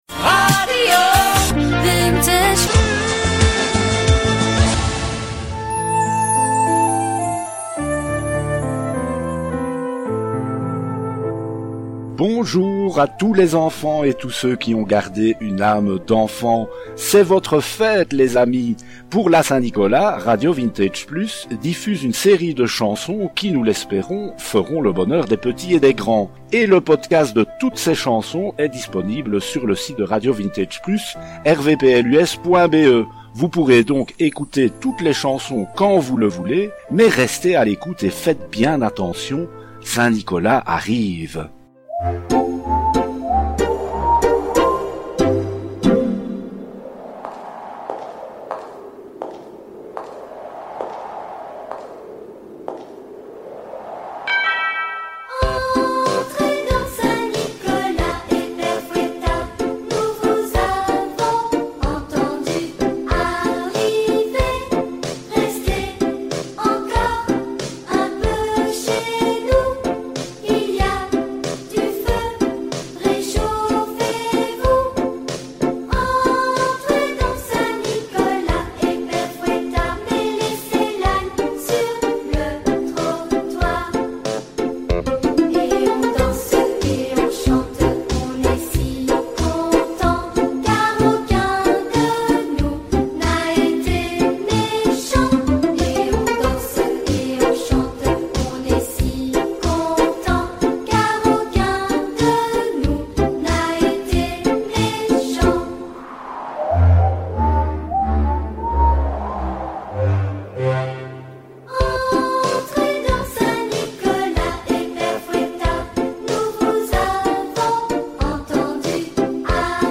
La légende de Saint-Nicolas en chansons - Saint-Nicolas dans les studios RV+ explique sa légende et celle du Père Noël et écoute ses chansons